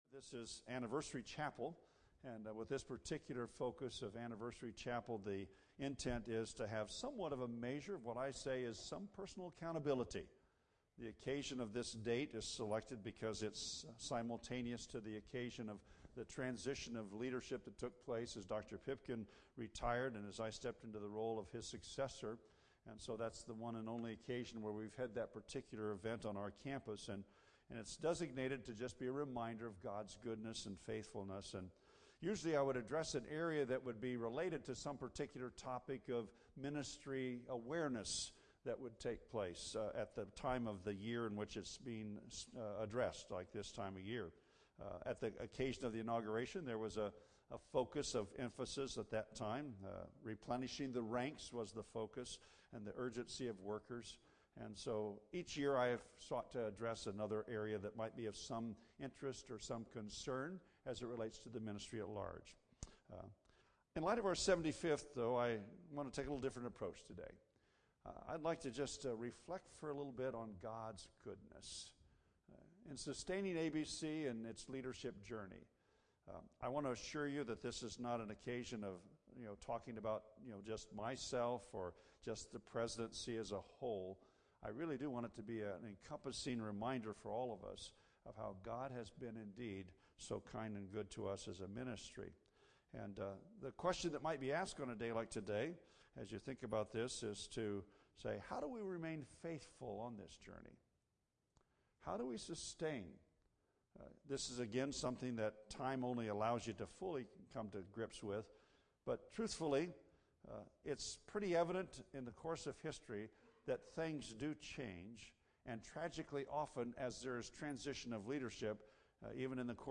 Chapel Message